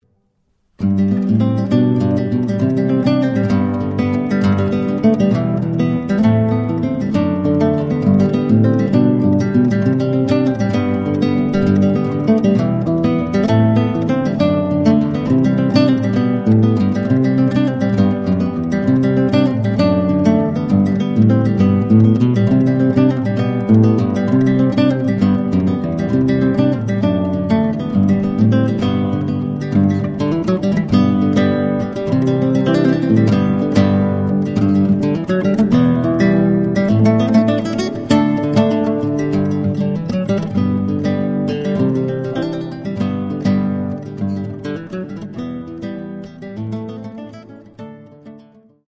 Instrumental Acoustic Guitar